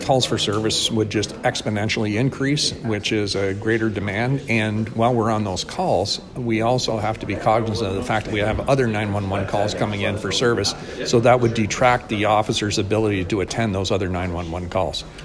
Police Chief Mike Callaghan says the statistics show that the Impact team is saving officers’ time and that without them the service provided would suffer.